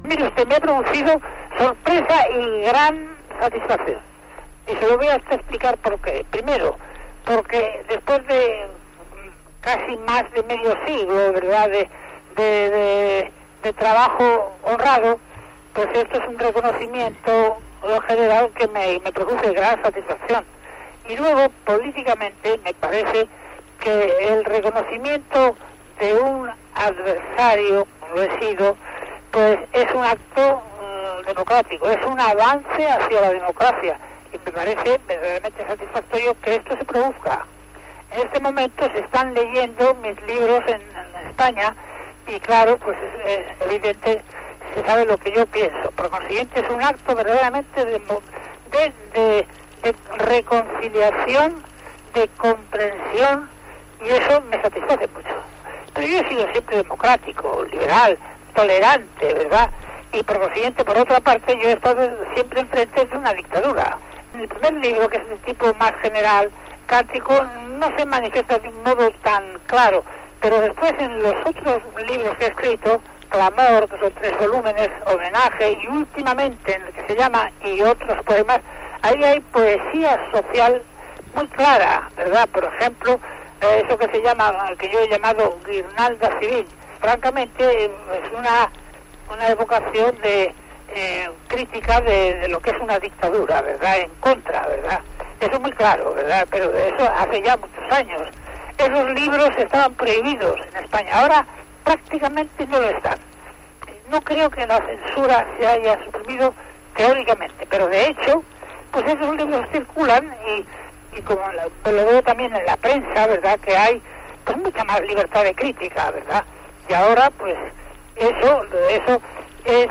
Entrevista telefònica al poeta Jorge Guillén, distingit amb el primer Premio Nacional de Poesía Miguel de Cervantes.
Cultura